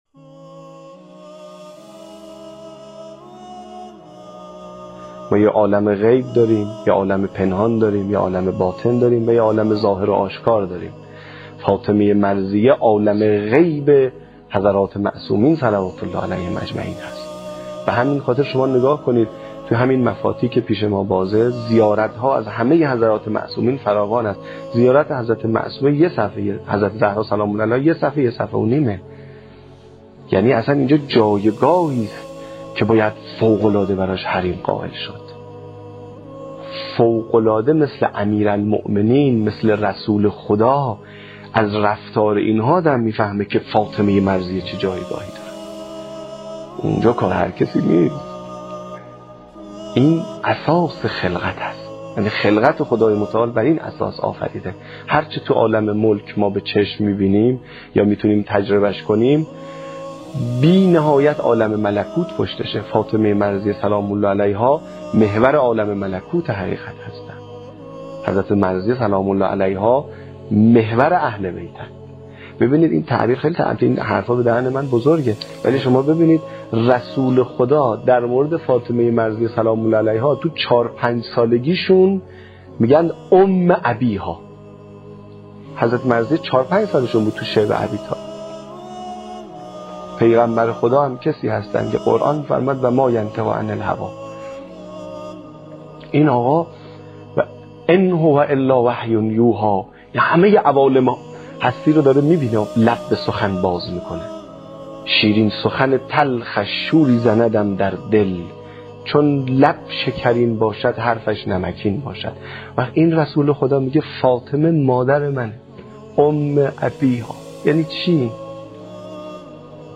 چندرسانه ای » گزارش و مستند